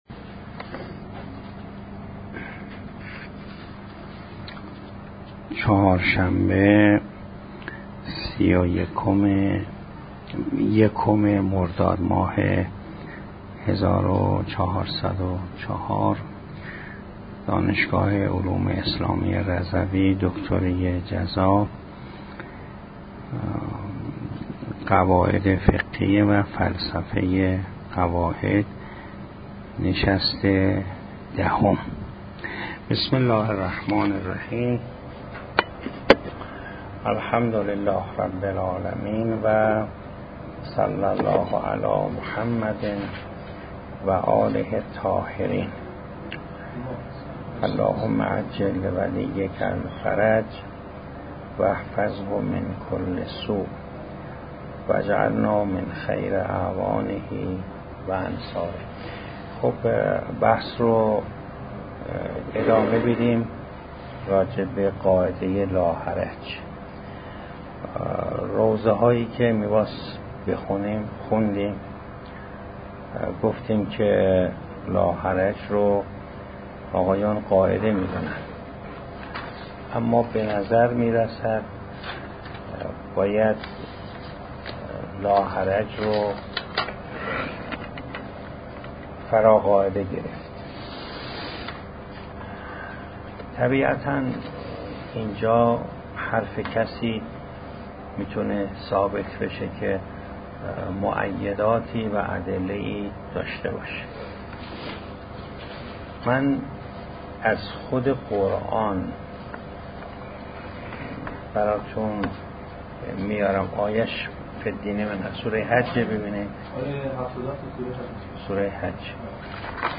دانشگاه علوم اسلامی رضوی فلسفه قواعد فقه جلسه دهم بیان فراقاعده بودن لاحرج و مویدات آن همراه با کنفرانس دانشجويان